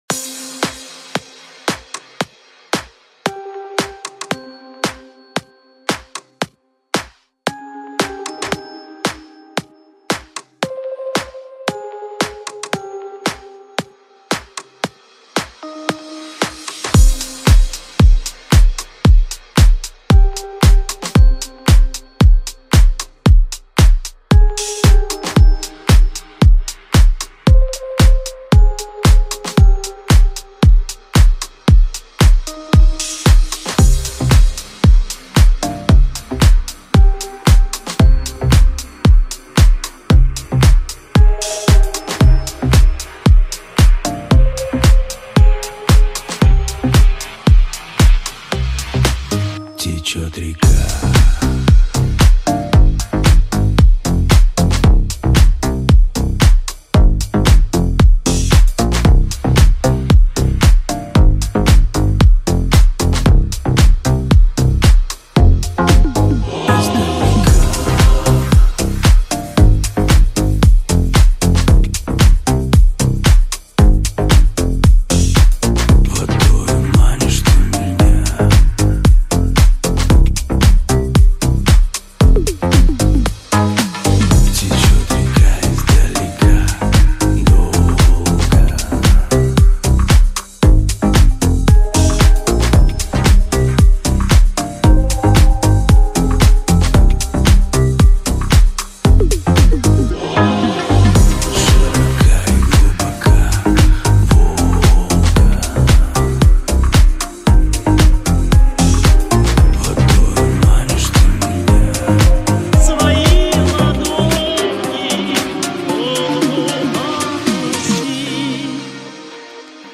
в стиле Диско